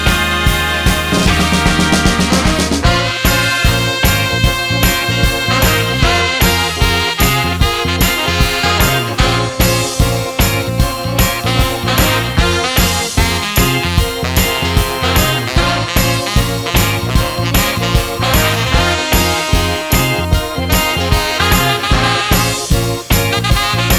no Backing Vocals Ska 3:06 Buy £1.50